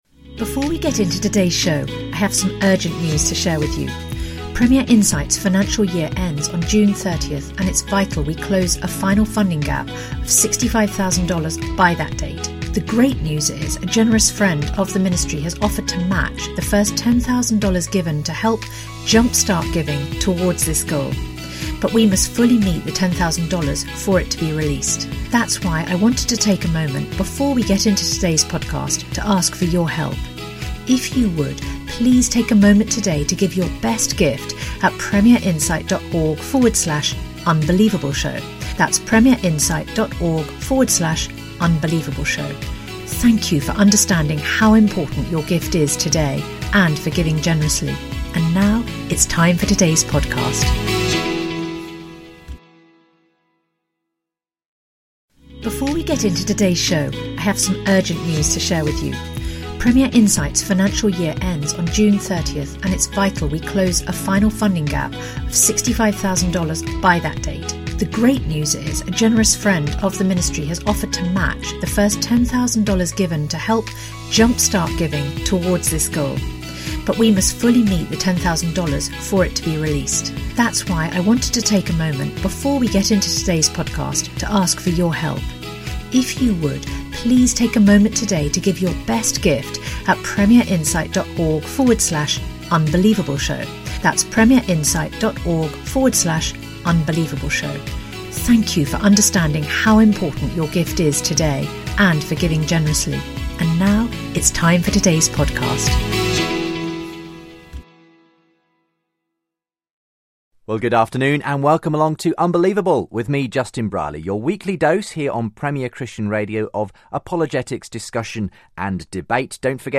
They debate whether Michael's belief in naturalism (all that exists is the material world) is a coherent worldview given the challenges posed by personhood, morality, free will and subjective states. They also discuss whether progress in science has eliminated the need for God.